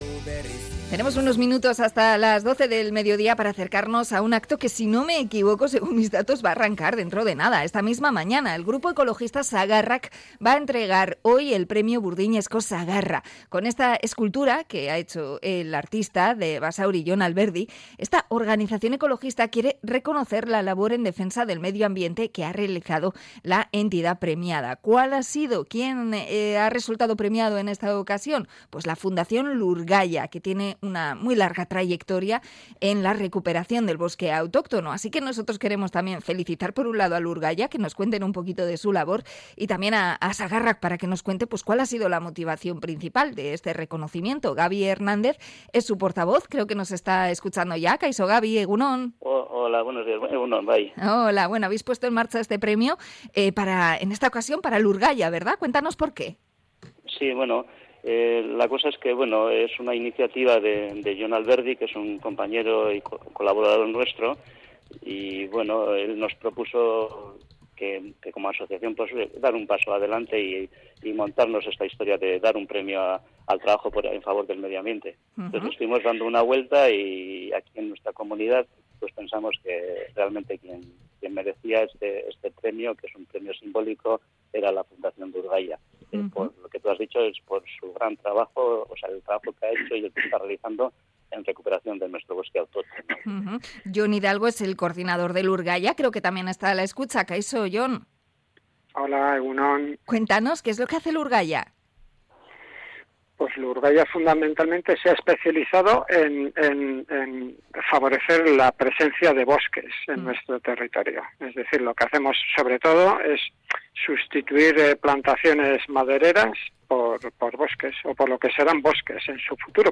Entrevista a las organizaciones Sagarrak y Lurgaia